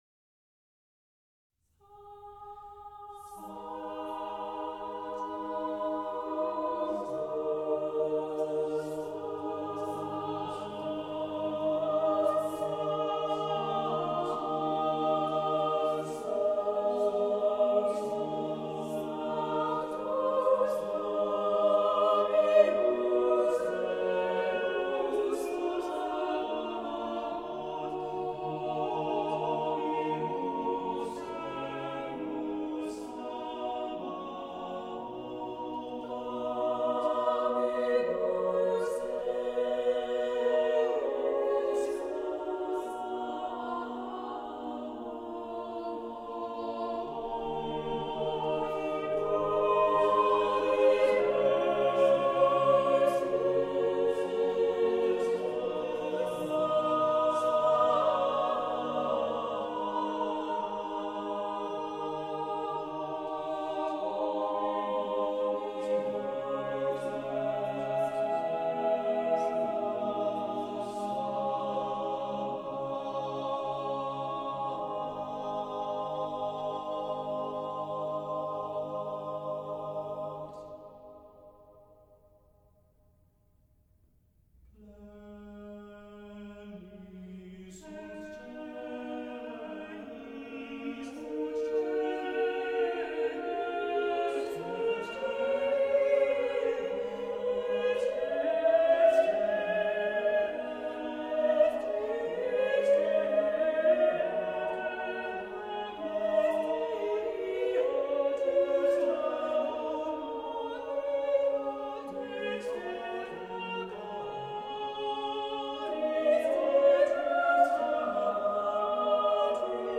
Vocal Ensemble